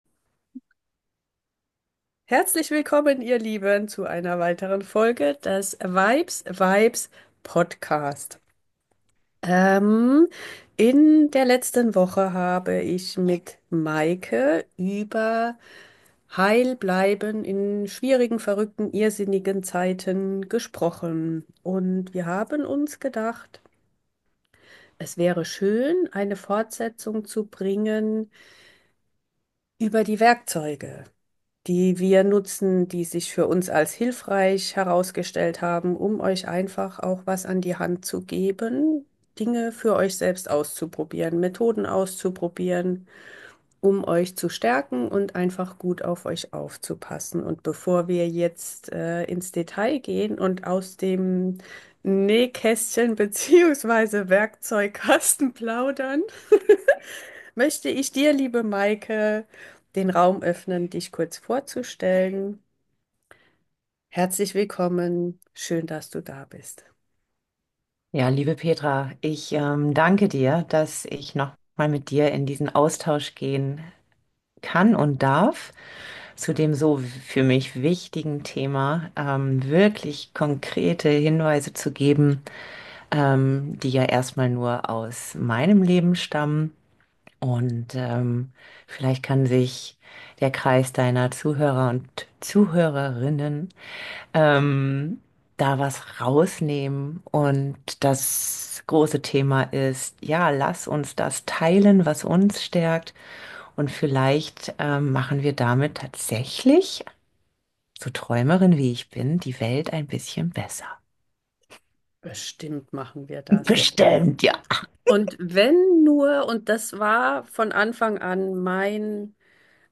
Die Zeit im Zoom war fast vorbei, da musste ein Ende her.